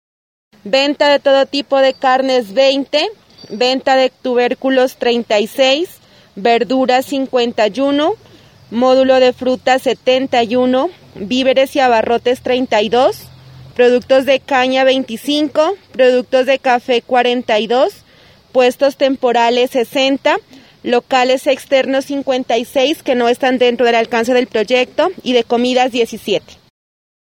Los integrantes de las veedurías ciudadanas del proyecto de construcción de la plaza de mercado de Sandoná y dos funcionarias de la Administración Municipal visitaron la obra este martes en la tarde.